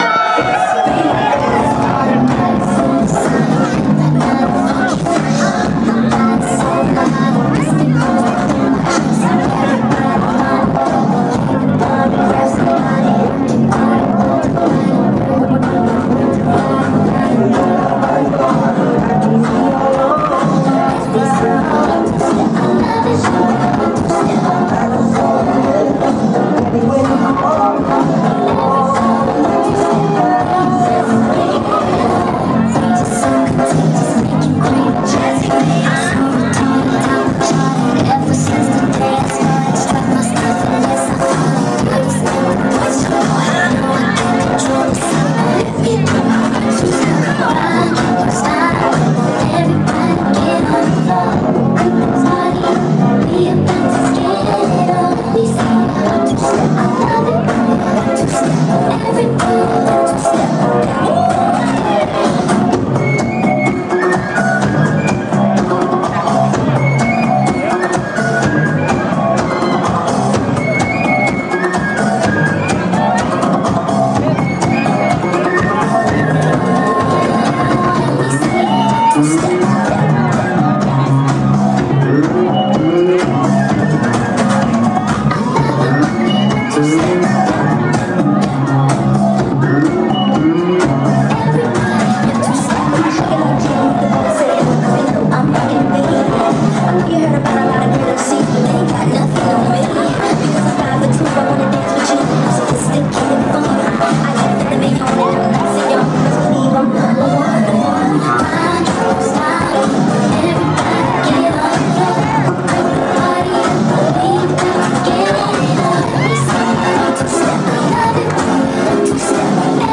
Mashup, Bootleg